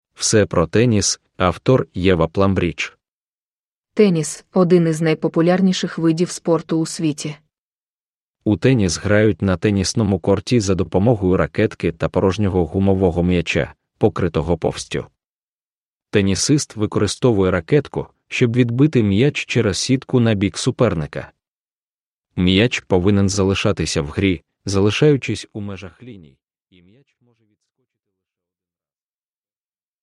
All about Tennis – Ljudbok – Laddas ner